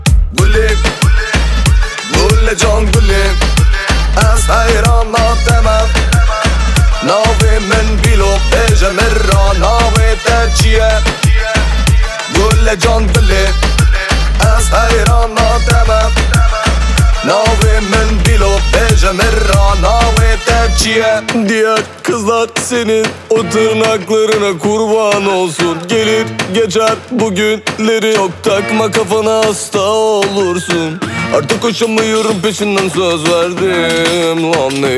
Жанр: Рэп и хип-хоп / Альтернатива
# Alternative Rap